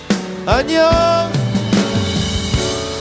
このゆちょんの「あんにょん」はぁ。